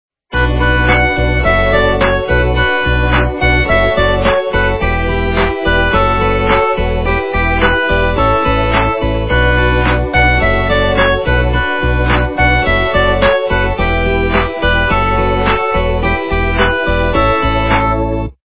- русская эстрада
качество понижено и присутствуют гудки.
полифоническую мелодию